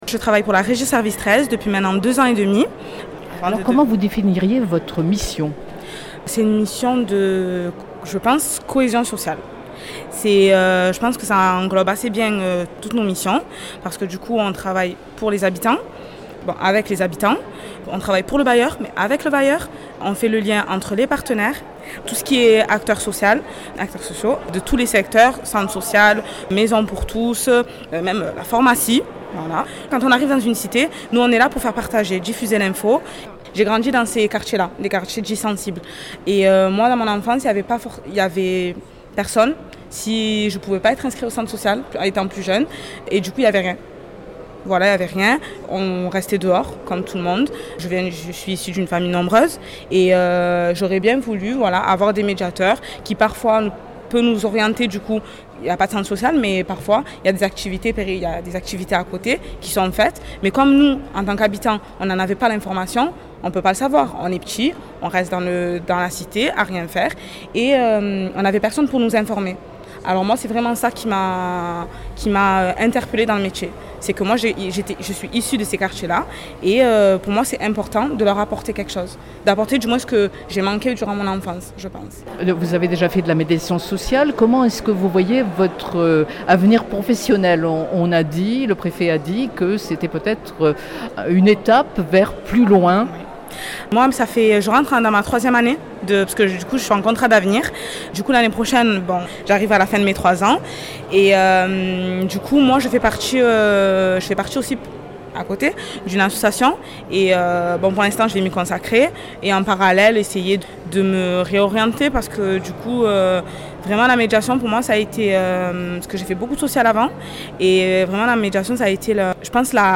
médiatrice sociale revient sur ses missions